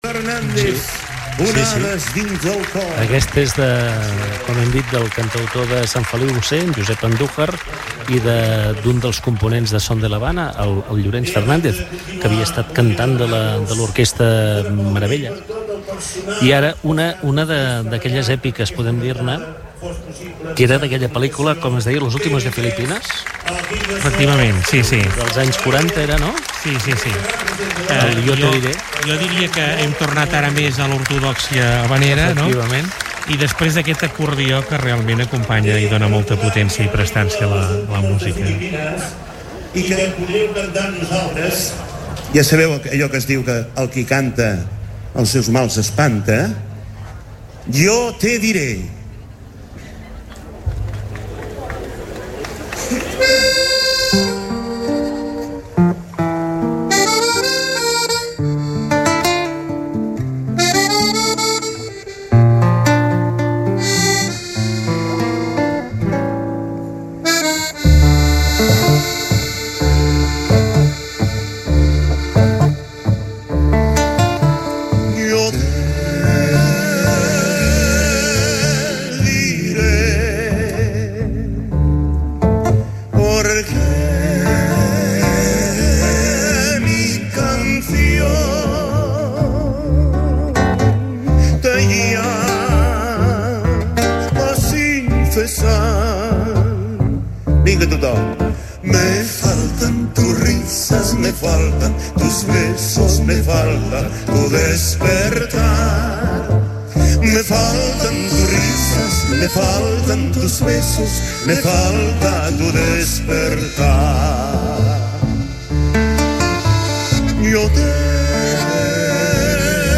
La cantada d’Havaneres de Calella de Palafrugell va arribar ahir a la seva 53ª edició, i Ràdio Capital va fer un programa previ i una retransmissió en directe
Aqueseta ja és la segona vegada que Ràdio Capital fa un programa especial per seguir en directe aquesta tradició.